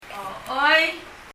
パラオ語 PALAUAN language study notes « understand 理解する No いいえ » yes はい ochoi [ɔ ? ɔ(:)i] 英） Yes 日） はい Leave a Reply 返信をキャンセルする。